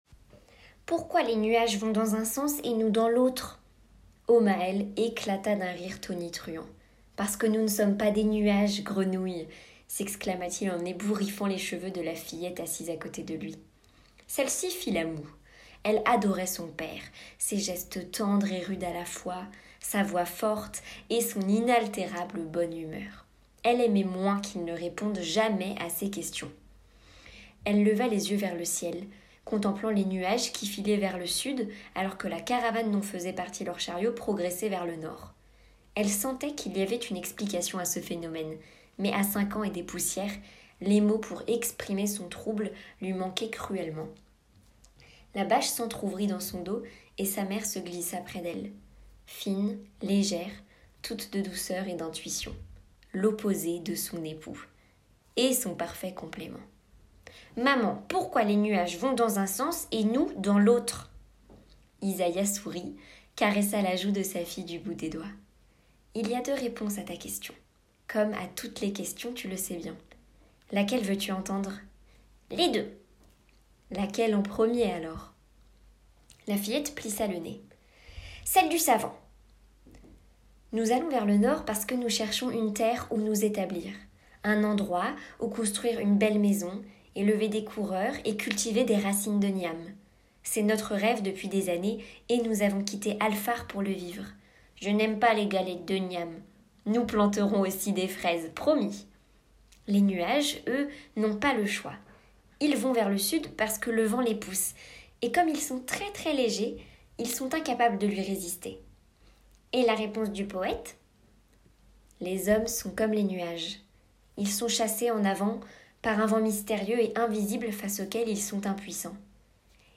Voix off lecture